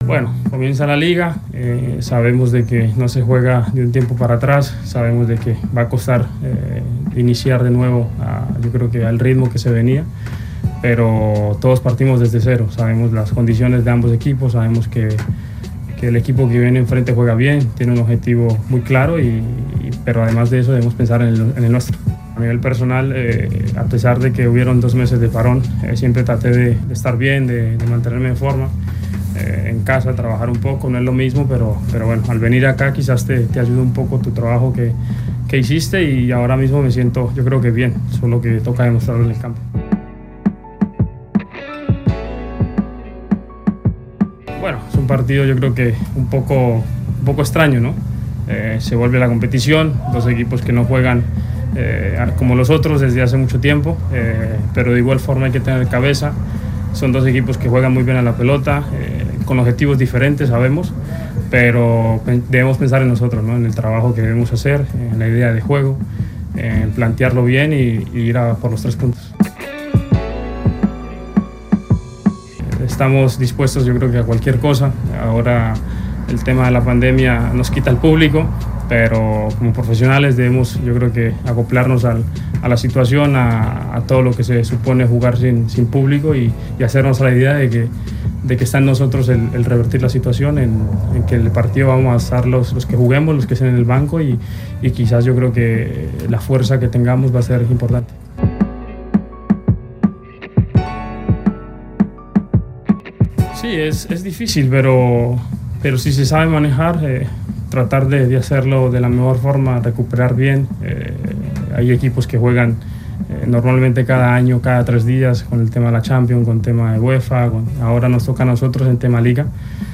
(Jeison Murillo, defensa del Celta de Vigo)